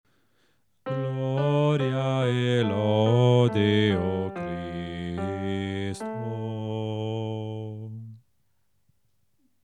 BASSI  –
CDV-101-Gloria-e-Lode-a-Te-o-Cristo-BASSI-3^-melodia.mp3